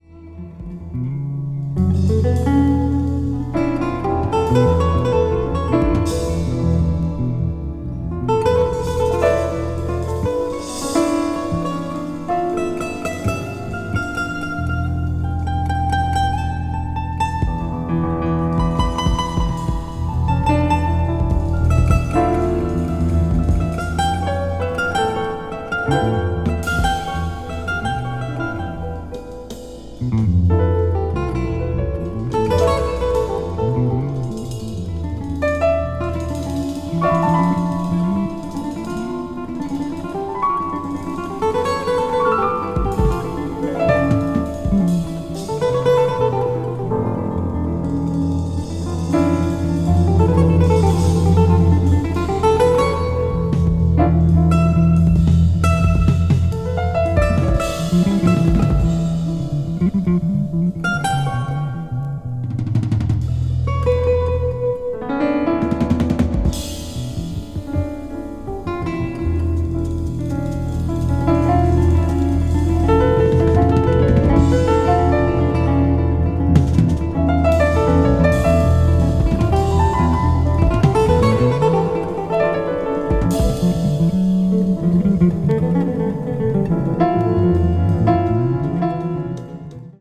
acoustic   contemporary jazz   new age jazz